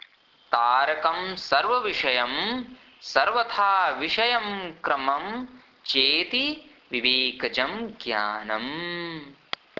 Chant  तारकं सर्वविषयं सर्वथाविषयक्रमं चेति विवेकजं ज्ञानम् ।।